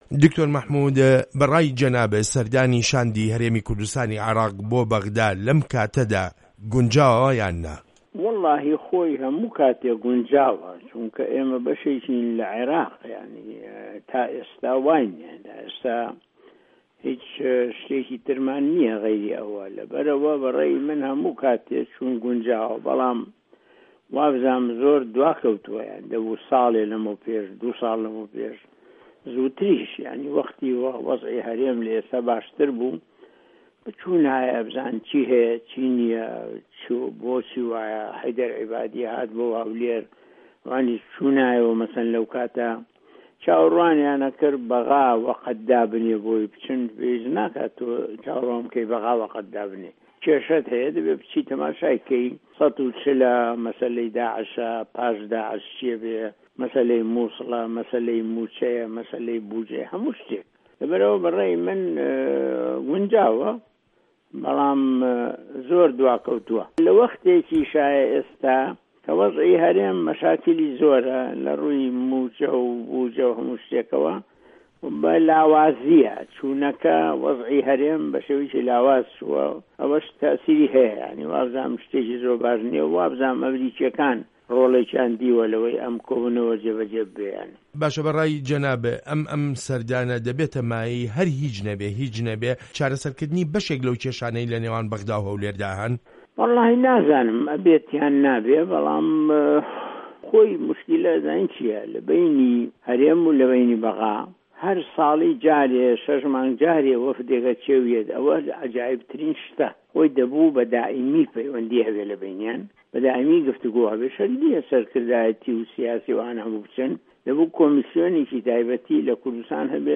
وتووێژ لەگەڵ دکتۆر مەحمود عوسمان